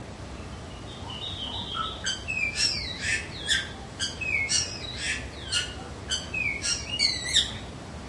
椋鸟
描述：这些鸟类于2017年9月24日上午（早上7点左右）在一个住宅区中间被记录下来。他们在房子之间或在房子之间飞行。我想在背景中也有一只木鱼。 使用连接到Olympus LS14的Sennheiser ME66 / K6进行录制，并使用Audacity进行一些基本编辑。有一些道路噪音，使用Ocenaudio减少了。
标签： 场记录 椋鸟 八哥
声道立体声